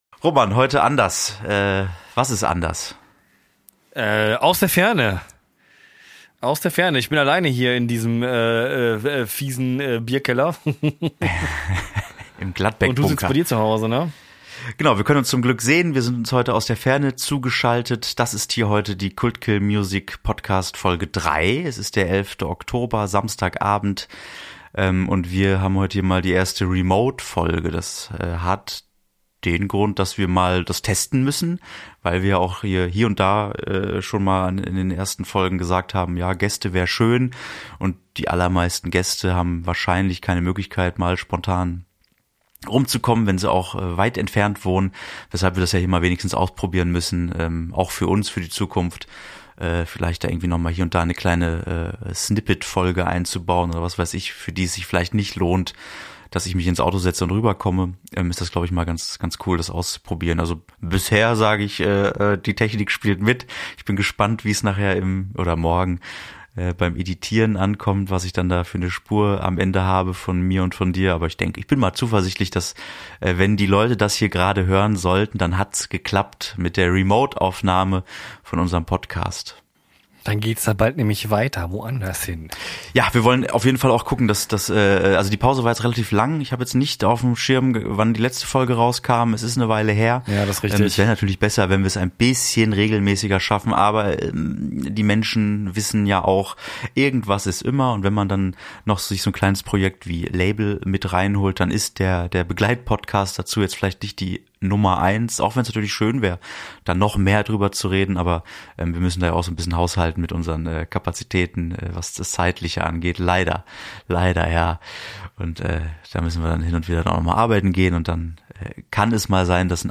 In unserer dritten Folge probieren wir zum ersten Mal einen Remote-Podcast aus, mit allem, was dazugehört.